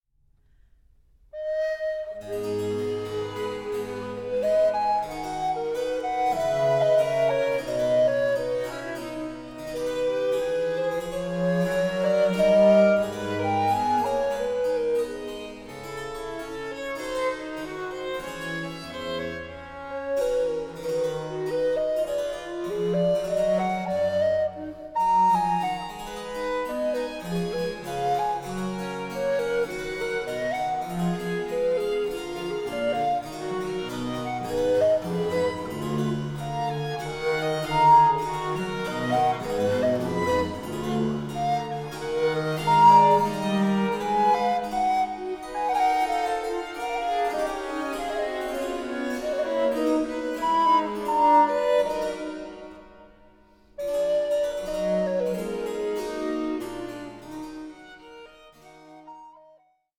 for flute, violin & basso continuo